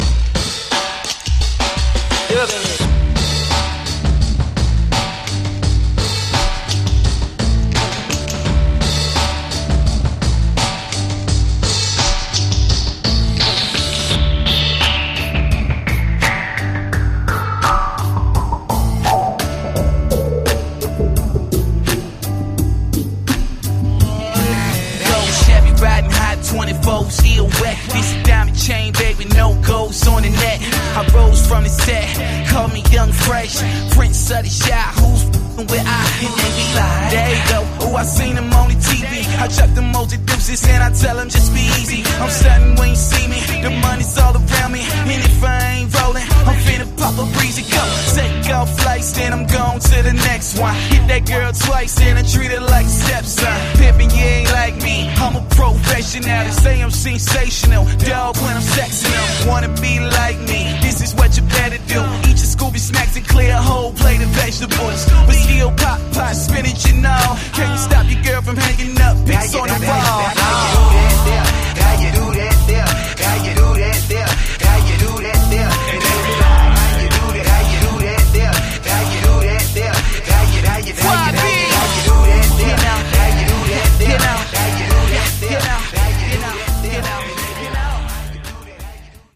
85 bpm
Clean Version